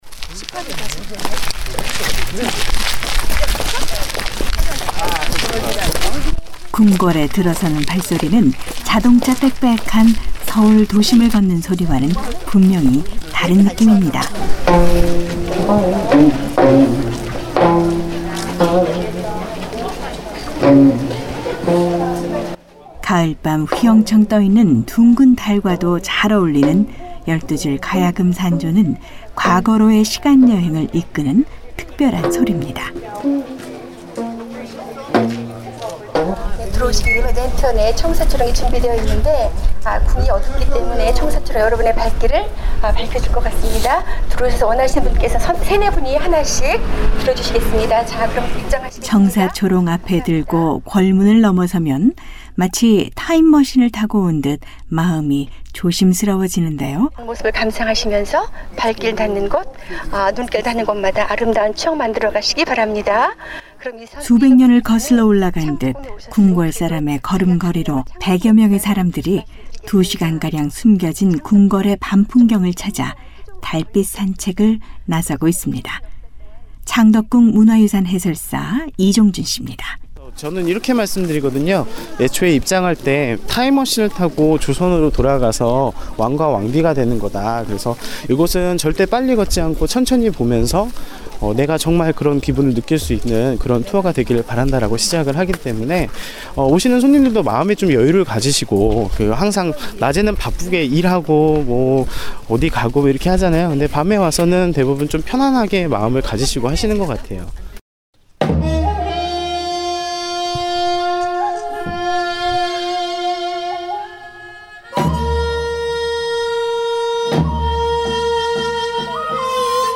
유네스코 세계문화유산으로도 지정된 ‘창덕궁’ 달빛 산책 현장.